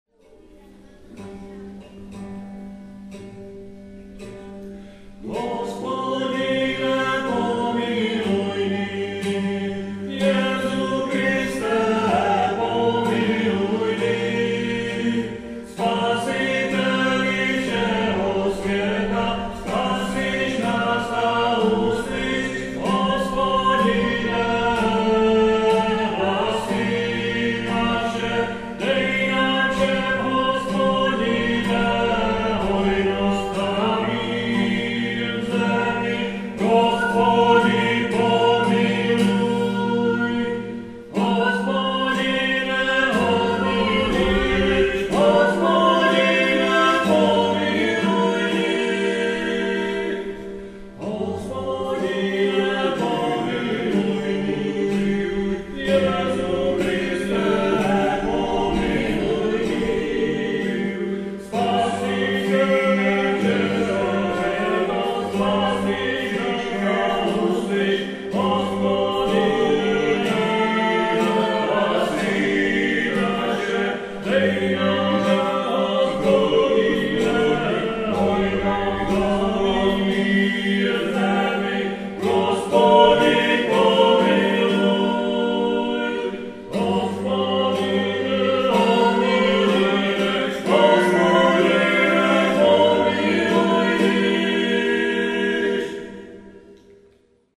1.9.2008 Vznik waldorfské školy v Českých Budějovicích